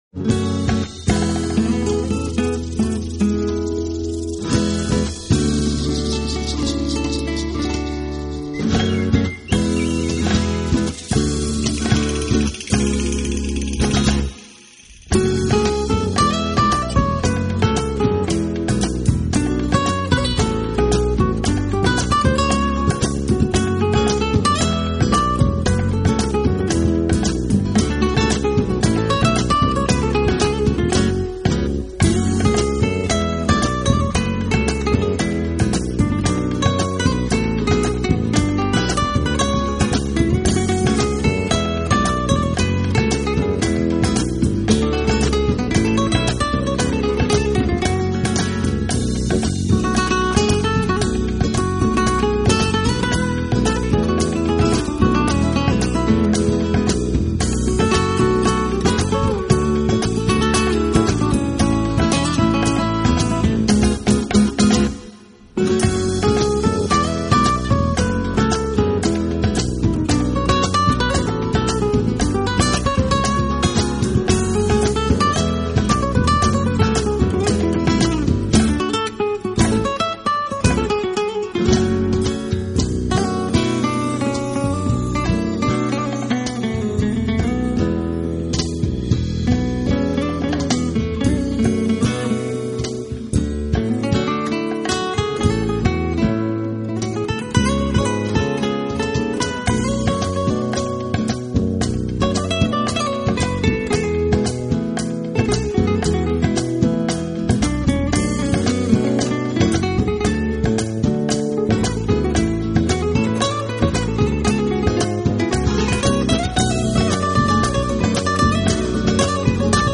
Genre: Instrumental
完完全全是吉他散发出来的魅力。